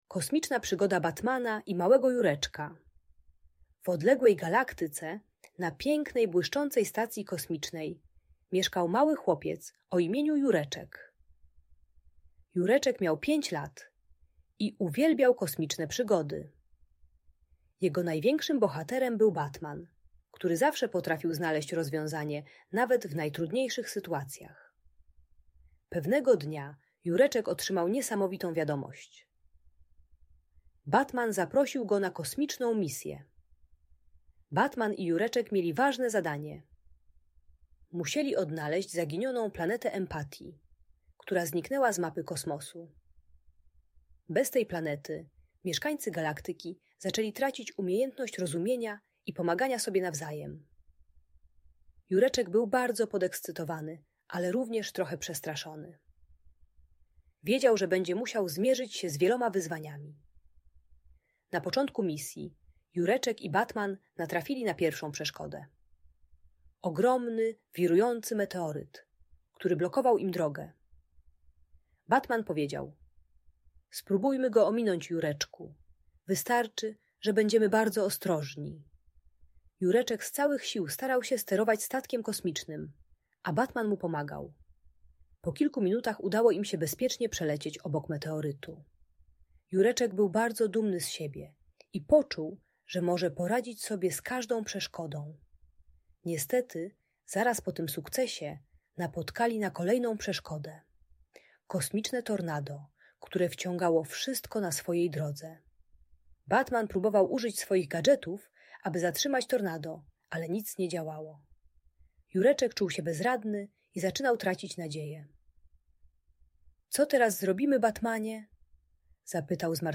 Kosmiczna Przygoda: Batman i Jureczek - Bunt i wybuchy złości | Audiobajka